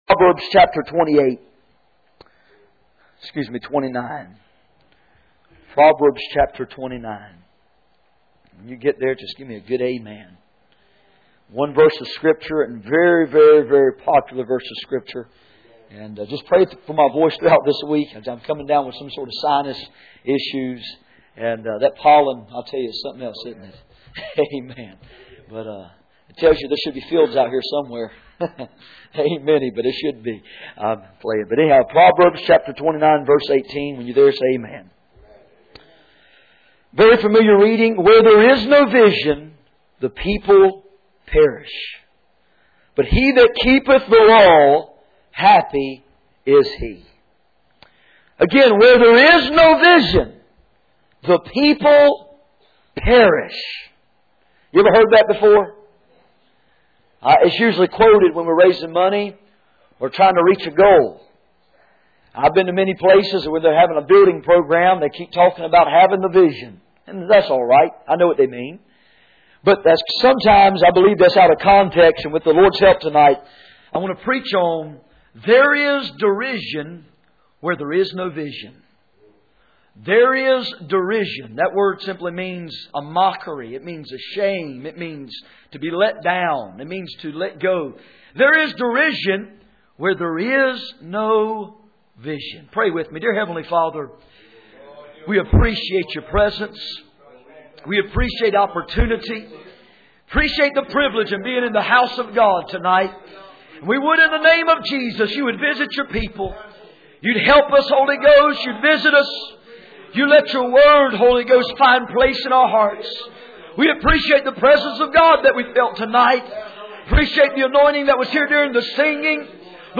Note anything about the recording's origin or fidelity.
Passage: Proverbs 29:18 Service Type: Sunday Evening